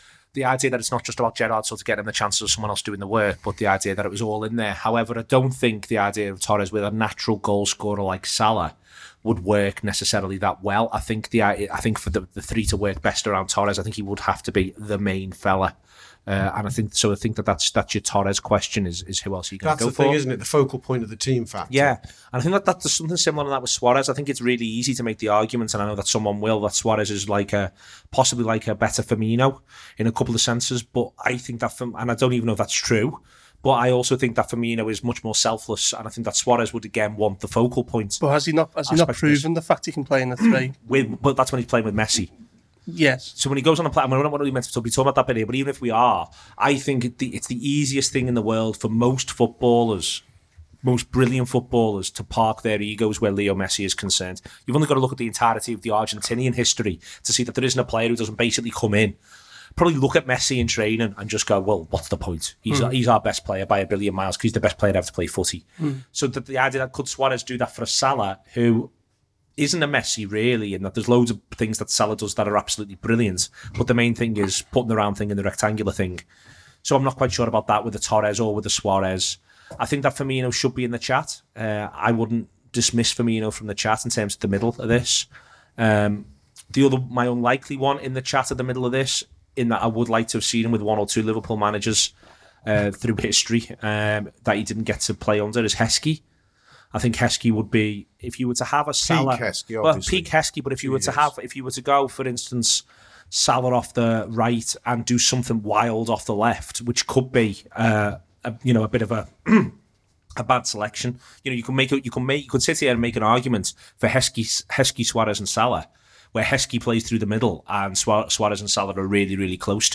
where a panel contributors are asked a question around ‘What is the greatest ever……’ and then argue their choice before it is put forward for a vote on social media.